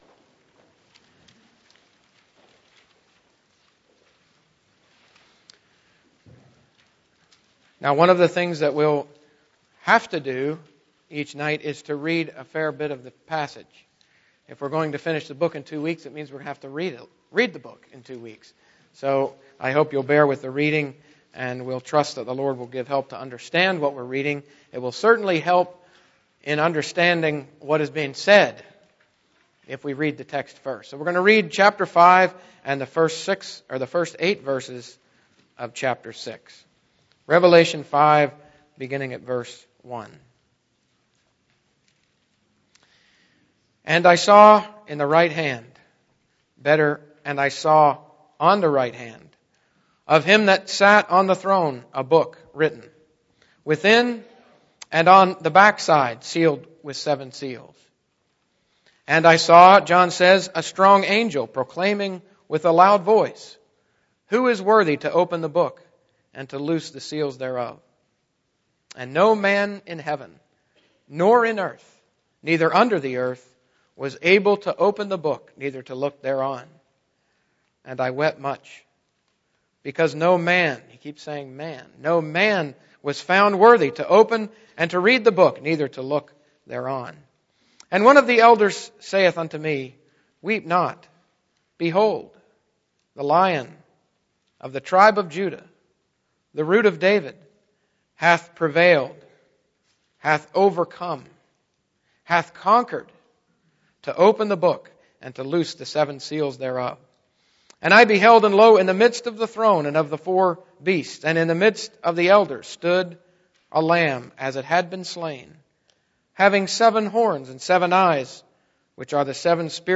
Series: Unveiling Revelation Service Type: Gospel Preaching Topics